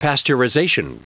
Prononciation du mot pasteurization en anglais (fichier audio)